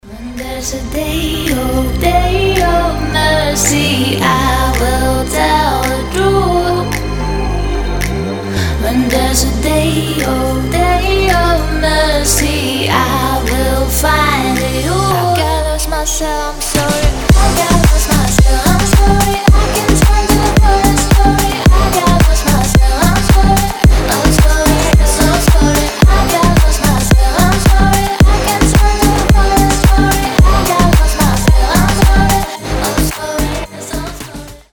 • Качество: 320, Stereo
deep house
женский голос
нарастающие
восточные
щелчки
slap house